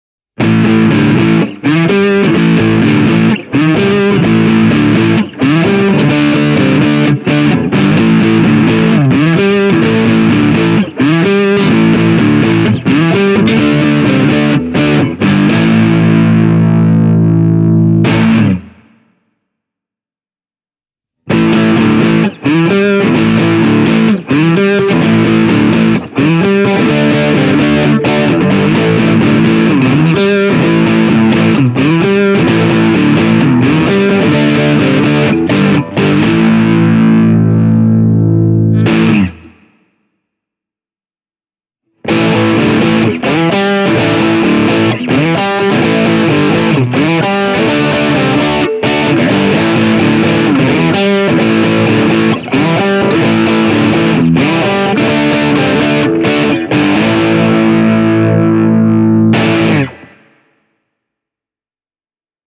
This Italia has fine ringing tone with a piano-like attack, which is never brittle or grating. These Wilkinson-pickups sound fantastic and offer enough output to take you from authentic Sixties-sounds (think Beatles, Byrds and early Who) all the way to more grittier stuff (think Paul Weller or Peter Buck).